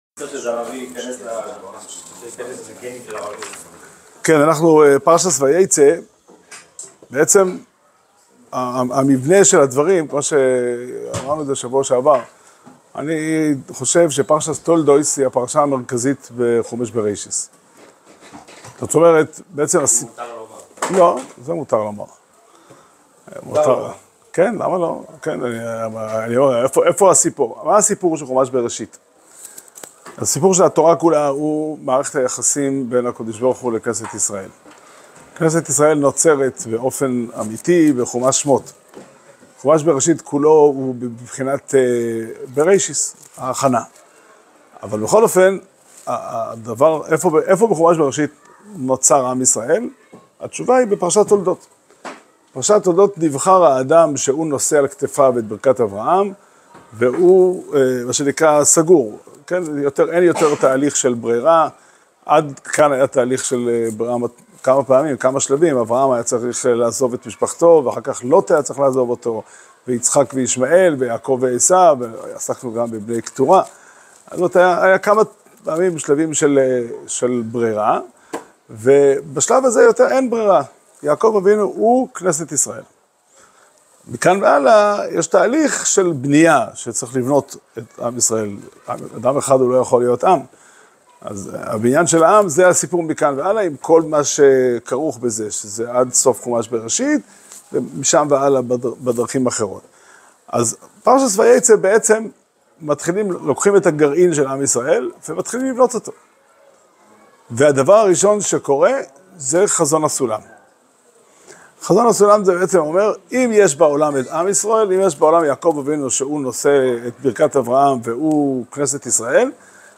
שיעור שנמסר בבית המדרש פתחי עולם בתאריך א' כסלו תשפ"ה